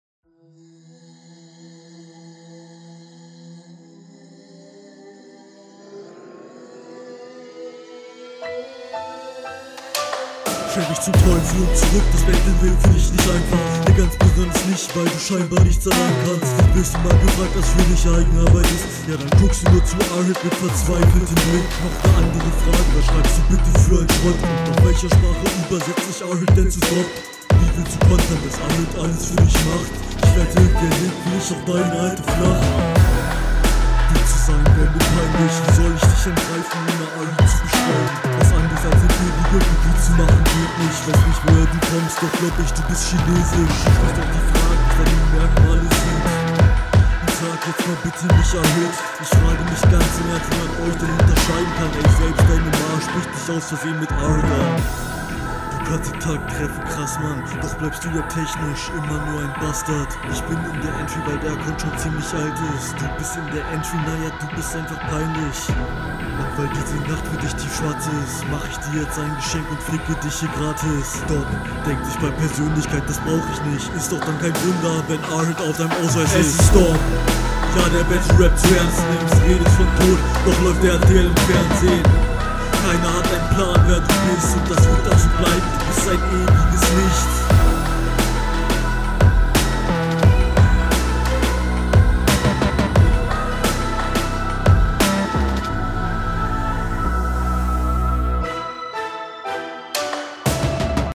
Flow: Trifft den Takt, Patterns sind recht Standard, nichts besonderes Text: Verstehe hier leider super …
Flow: aufjeden on point, kann man sich gut anhören Text: fand ich okay, von wegen …